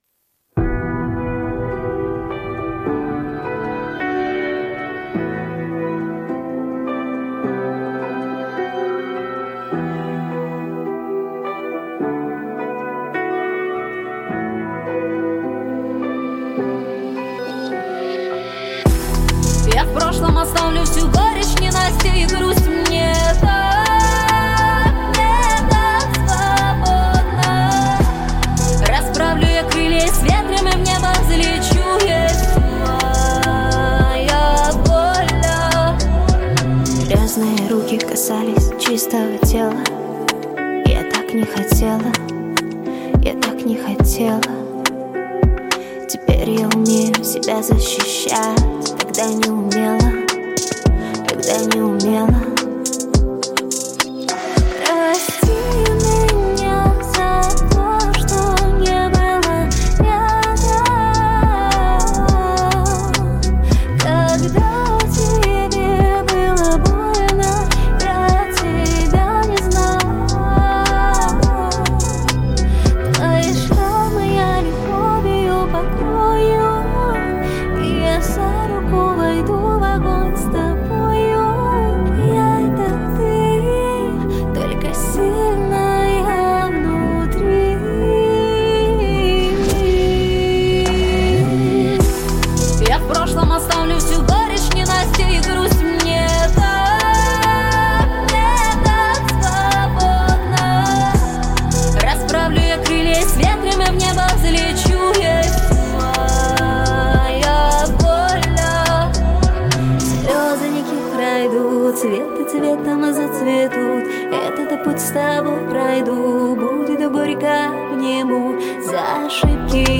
Пол Женский
Вокал Профессионал